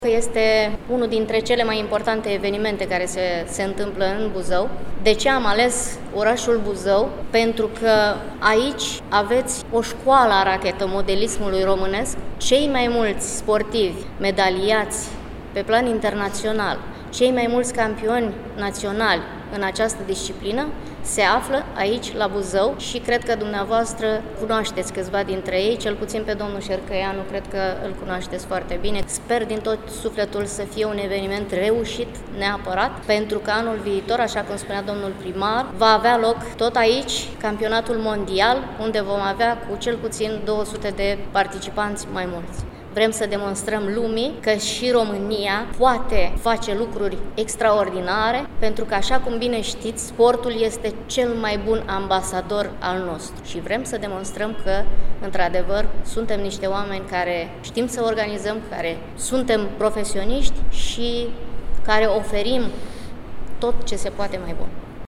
În cadrul unei conferințe de presă organizată la Primăria Buzău